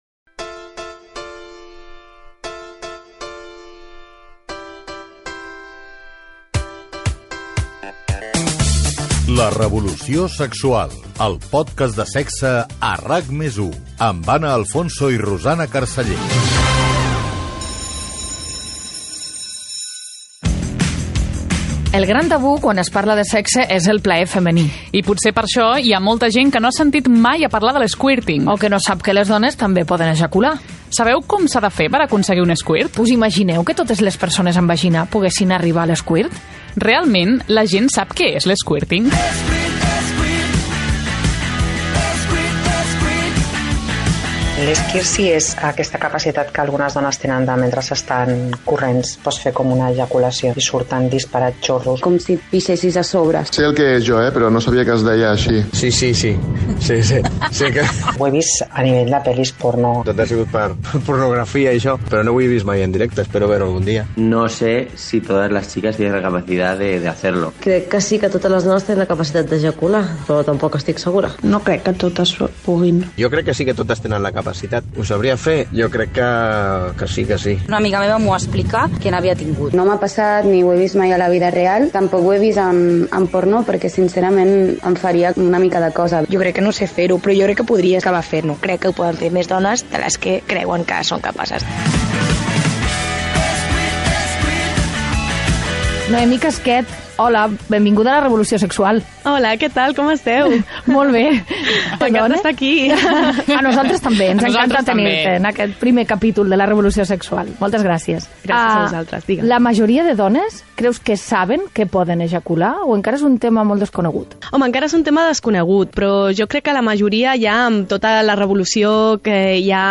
Careta del programa, presentació del programa dedicat a l'"Squirt", l'ejaculació femenina.
Divulgació